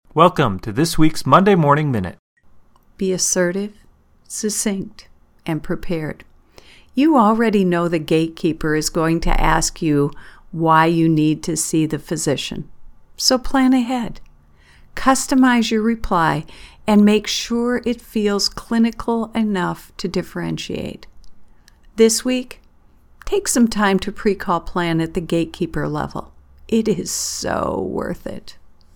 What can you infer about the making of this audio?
Studio version: